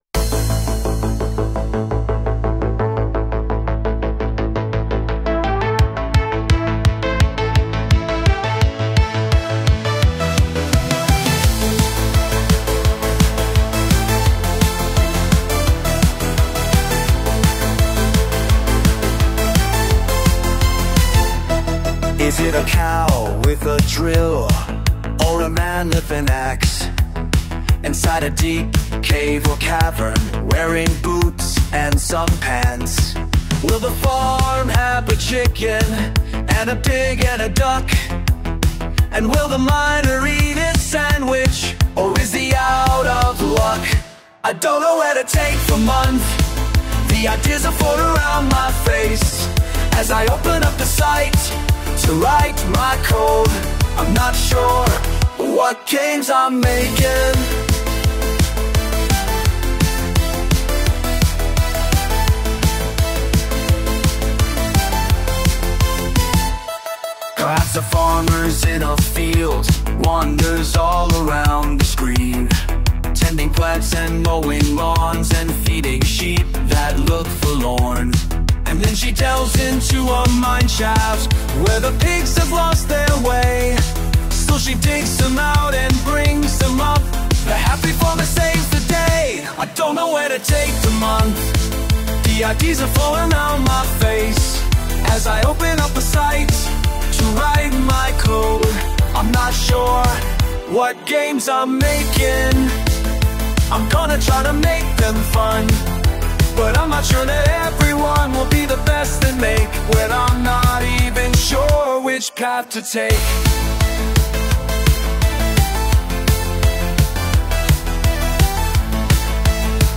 It's not "too" bad, now, and .. sure is one hell of an improvement.. but it still has that squelchiness to the audio. It frequently seems to drop a fraction of a second and that hurts the overall quality, IMO.